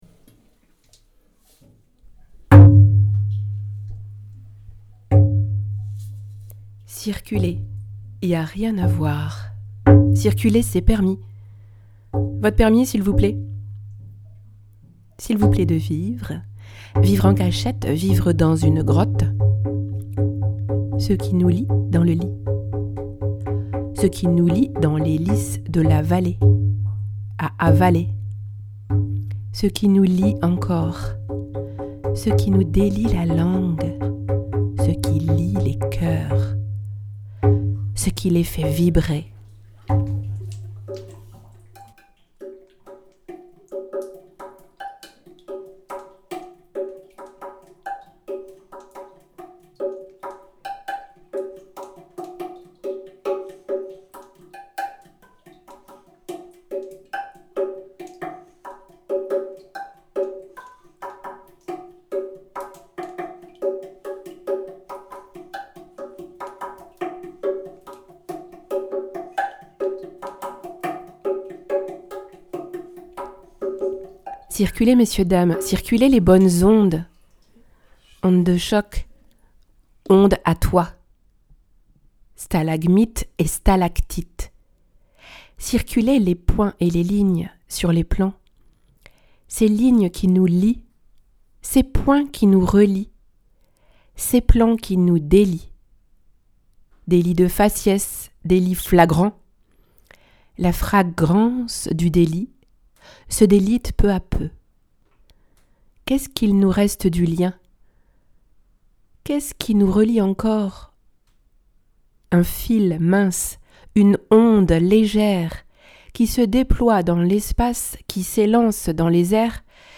Série ACOUSNESIE, Les sons Pacifique : -Boucan du caillou (2024) Oeuvre sonore où les caillloux prennent la parole, réalisée en Nouvelle-Calédonie pendant la crise nickel et les émeutes de mai 2024.